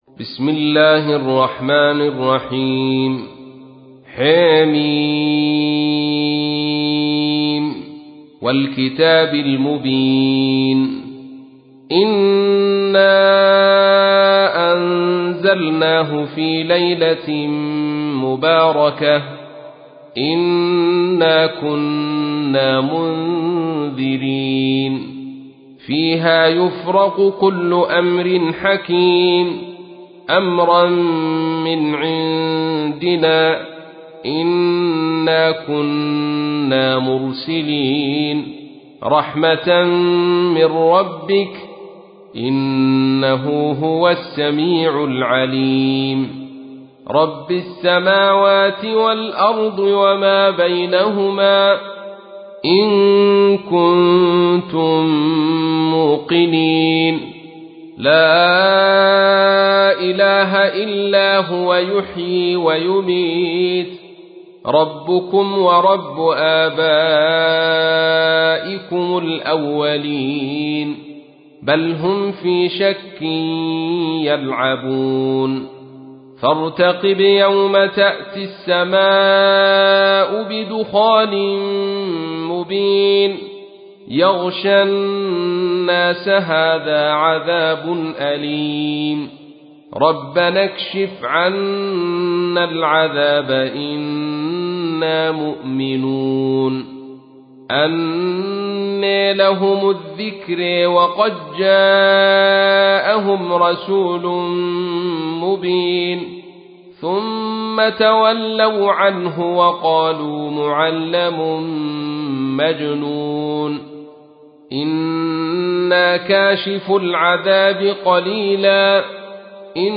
تحميل : 44. سورة الدخان / القارئ عبد الرشيد صوفي / القرآن الكريم / موقع يا حسين